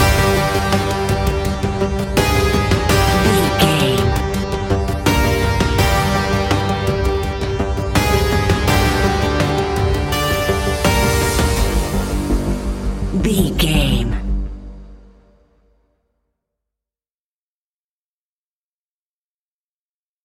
In-crescendo
Aeolian/Minor
ominous
dark
eerie
electronic music
Horror Pads
Horror Synths